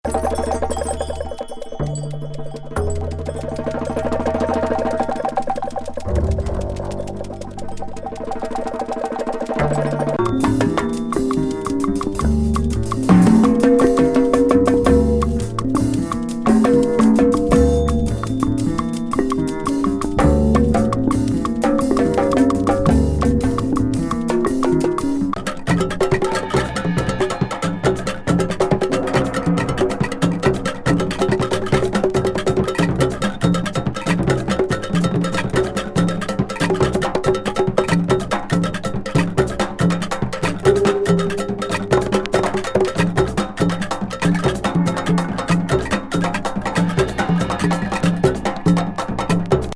分離の良さが四方八方感を煽ります。